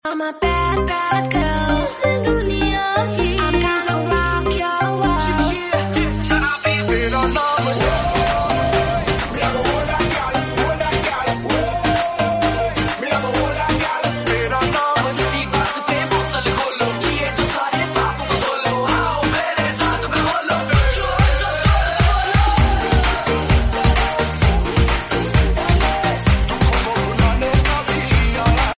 Bollywood Music